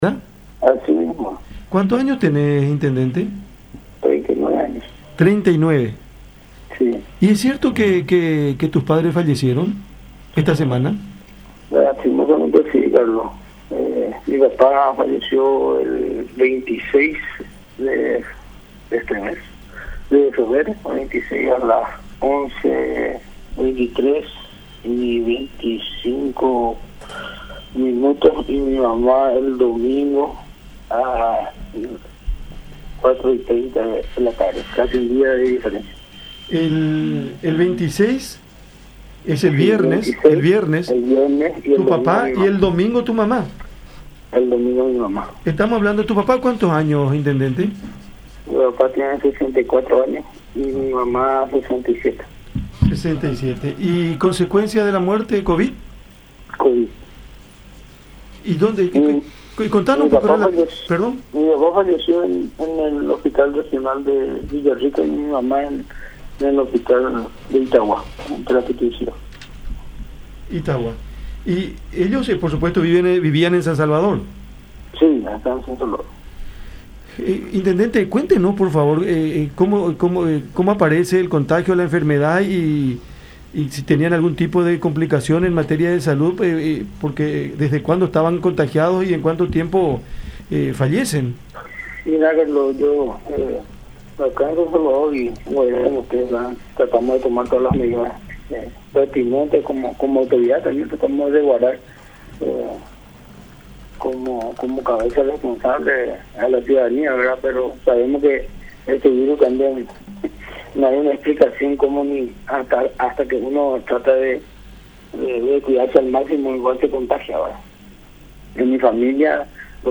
Pasé muchas cosas con él acá en Guairá”, manifestó Óscar Miranda Cáceres, intendente de San Salvador, en diálogo con La Unión.